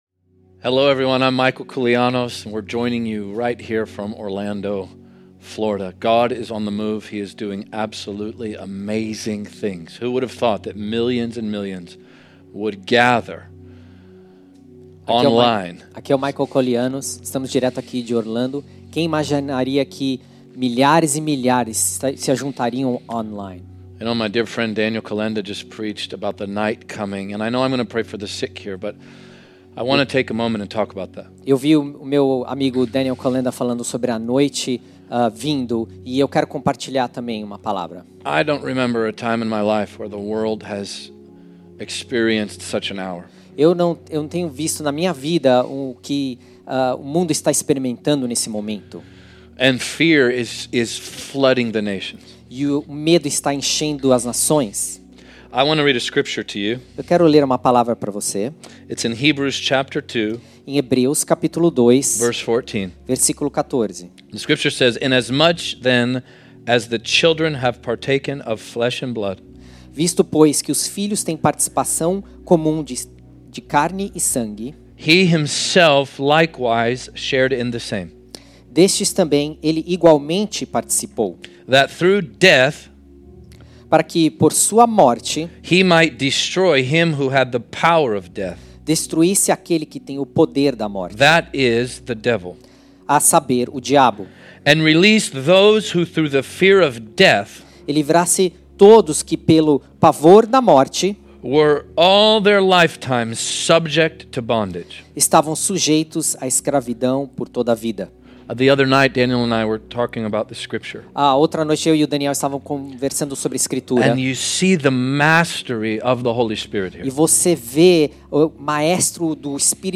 The sermon culminates in a call for healing, inviting the sick to receive prayer and believe in Jesus' power to heal.